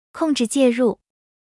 audio_engage.wav